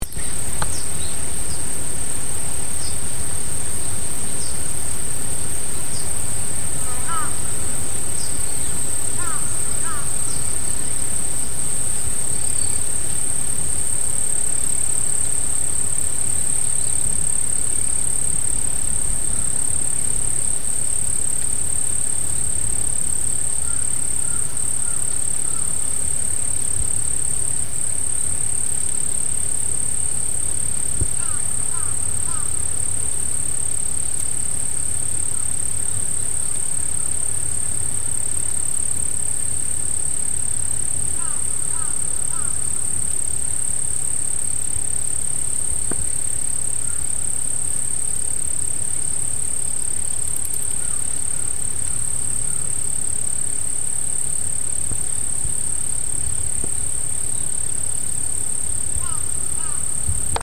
A summer morning in the rice fields
Recorded on a summer morning in the middle of a beautiful rice field stretching as far as the eye can see. It felt like a magnificent sound installation, with crows and insects singing.
Recorded in Niigata, Japan